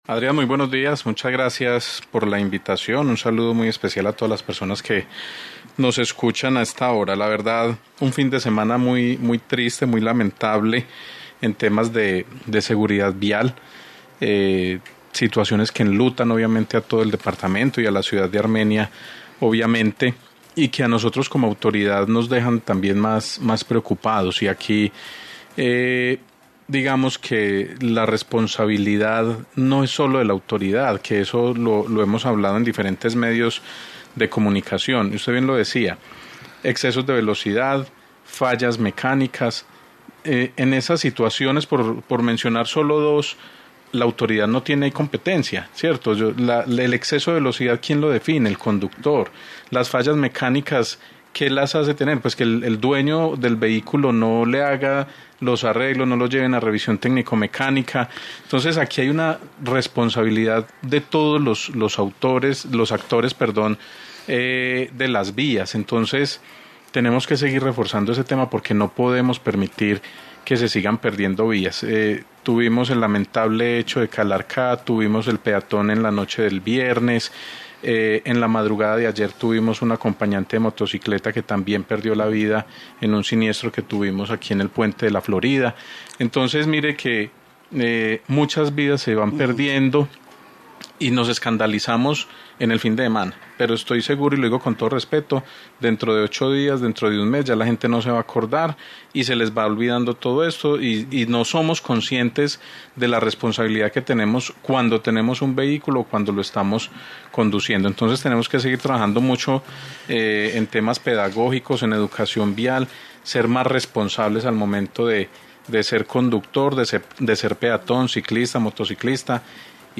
Daniel Jaime Castaño, director de Setta, Armenia
En Caracol Radio hablamos con el secretario de tránsito y transporte del Quindío Daniel Jaime Castaño que señaló “La verdad, un fin de semana muy triste, muy lamentable en temas de seguridad vial, situaciones que enlutaron obviamente a todo el departamento y a la ciudad de Armenia, obviamente, y que a nosotros como autoridad nos dejan también más más preocupados y aquí digamos que la responsabilidad no es lo de la autoridad, que eso lo hemos hablado en diferentes medios de comunicación.